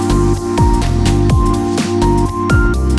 electronic.mp3